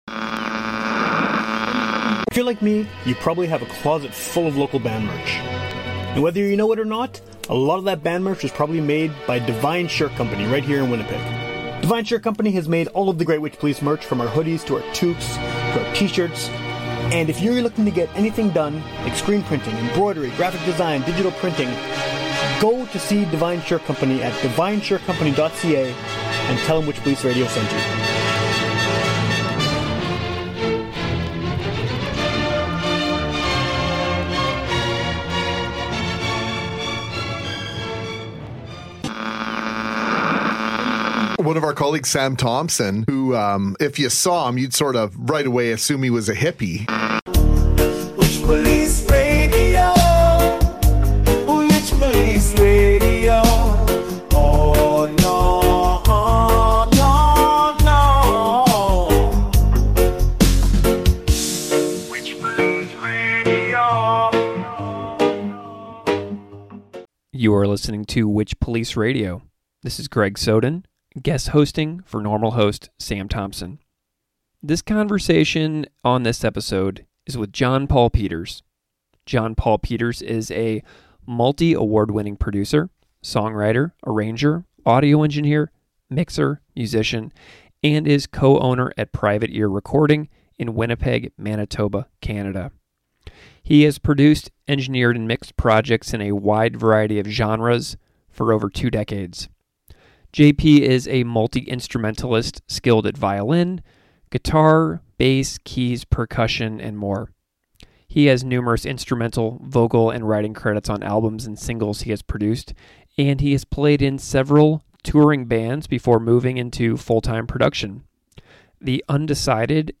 but you'll hear a few more guest-hosted interviews throughout the summer